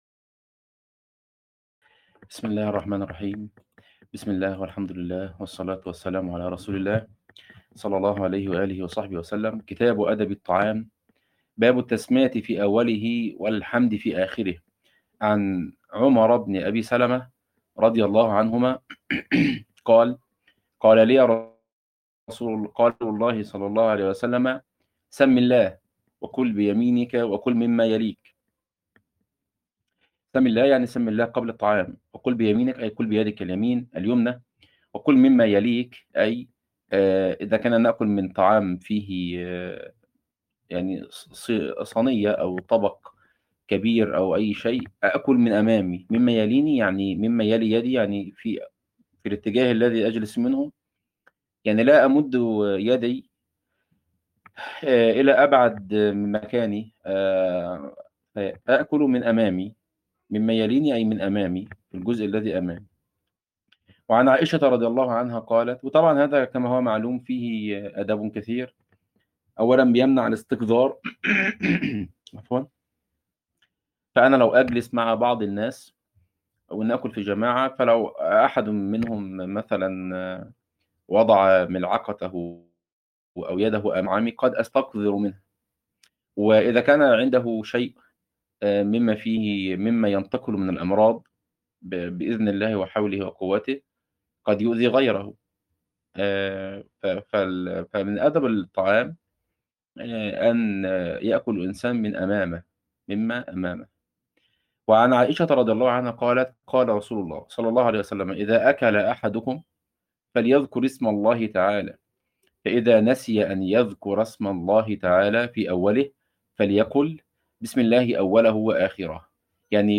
عنوان المادة الدرس 23 | دورة كتاب رياض الصالحين تاريخ التحميل الجمعة 27 يونيو 2025 مـ حجم المادة 47.40 ميجا بايت عدد الزيارات 99 زيارة عدد مرات الحفظ 70 مرة إستماع المادة حفظ المادة اضف تعليقك أرسل لصديق